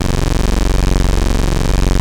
OSCAR 1  F#1.wav